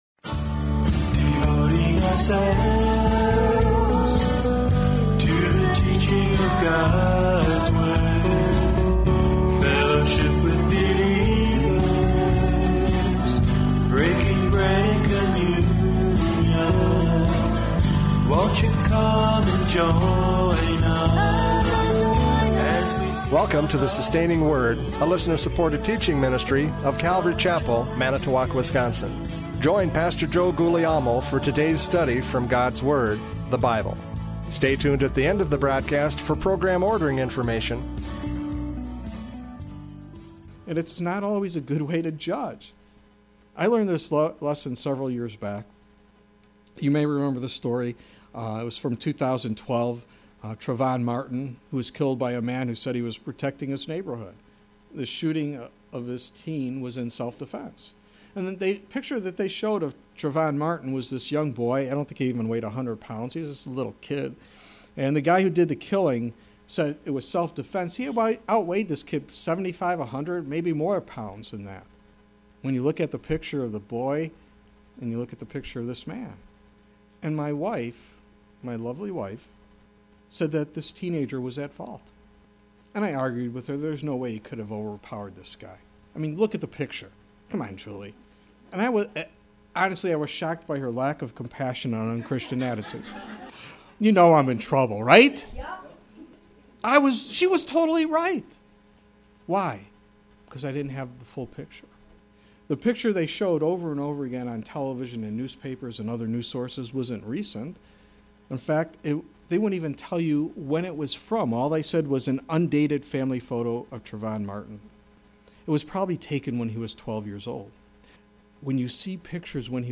John 7:14-36 Service Type: Radio Programs « John 7:14-36 Debating Jesus!